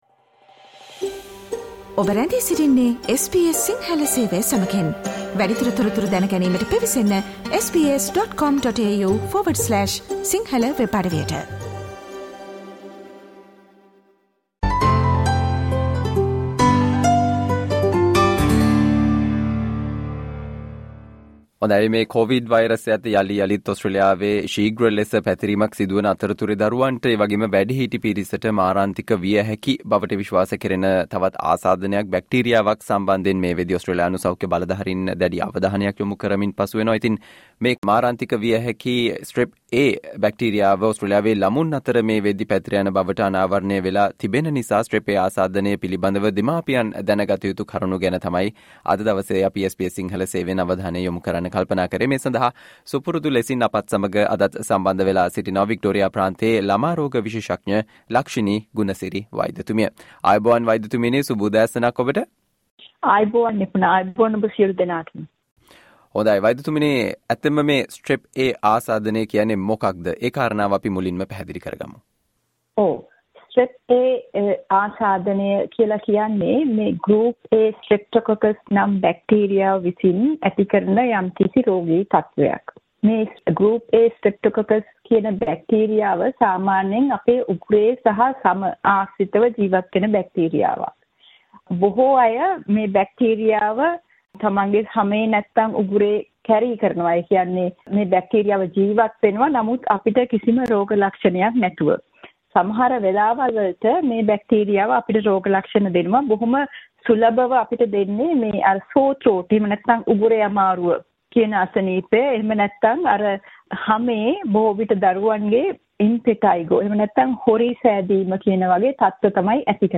ළමුන්ට සහ වැඩිහිටි පිරිසට මාරාන්තික විය හැකි සහ කොවිඩ් වෛරසය අතරතුරේ ඕස්ට්‍රේලියාවේ ළමුන් අතර මේ වනවිට ශීග්‍රයෙන් පැතිර යන බවට අනාවරණය වී තිබෙන Strep A අසාදනය පිළිබඳව දෙමාපියන් දැනගත යුතු කරුණු සම්බන්ධයෙන් SBS සිංහල සේවය සිදු කල සාකච්චාවට සවන් දෙන්න